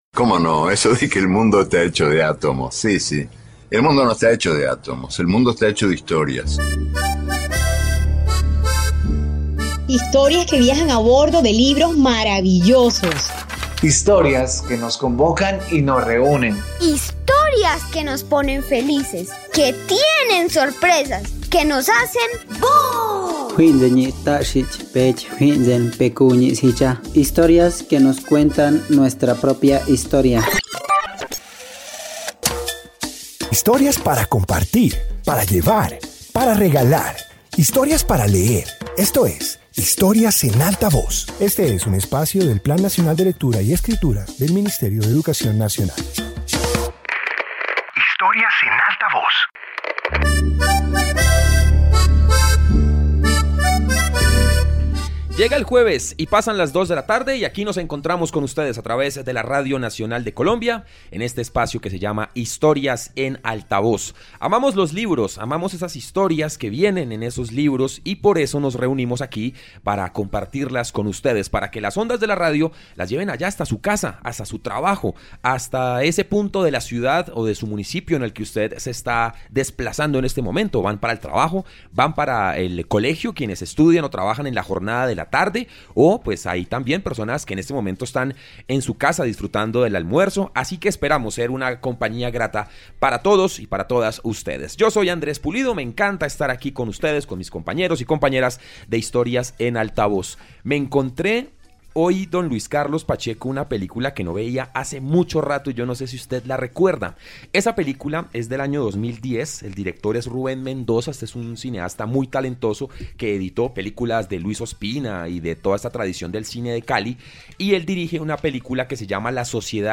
Introducción Este episodio de radio comparte relatos sobre personas que impulsan cambios en sus comunidades. Presenta iniciativas que reflejan liderazgo, solidaridad y compromiso con el bienestar colectivo.